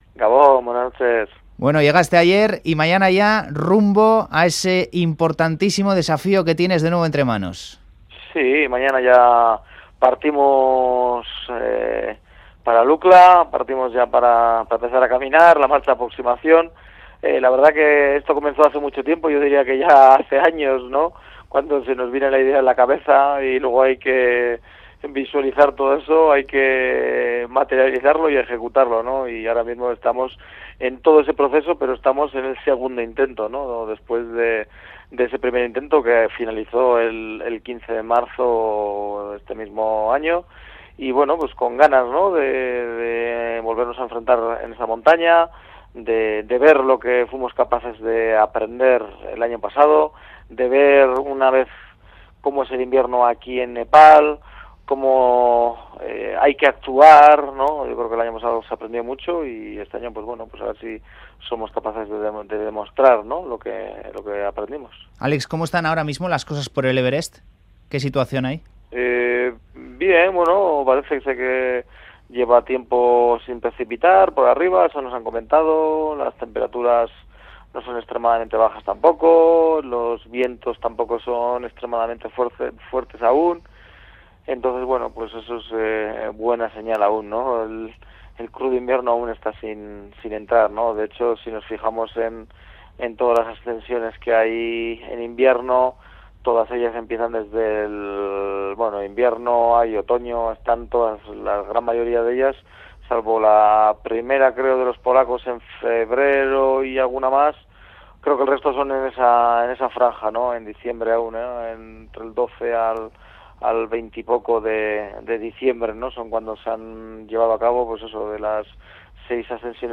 Audio: El vizcaíno atiende a Fuera de Juego desde Katmandú en la previa de iniciar el reto de convertirse en el primer alpinista en hollar la cima del Everest en invierno y sin oxígeno artificial